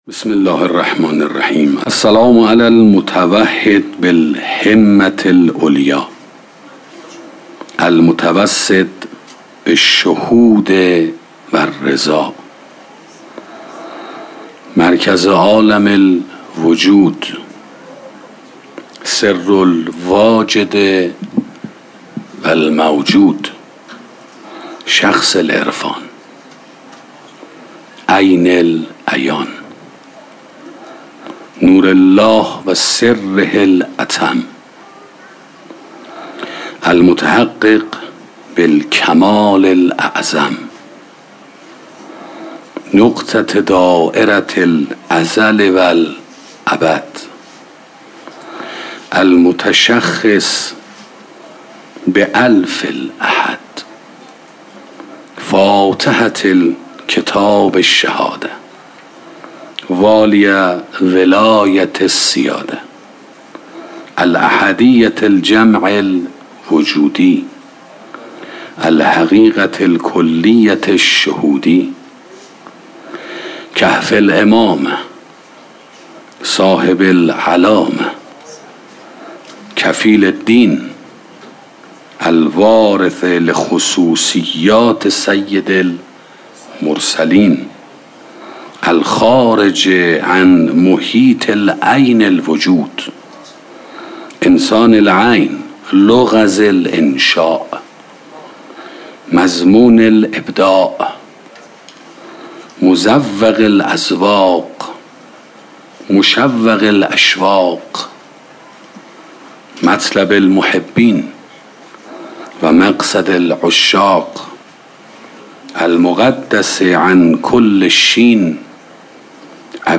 سخنرانی سلوک ثاراللهی 5 - موسسه مودت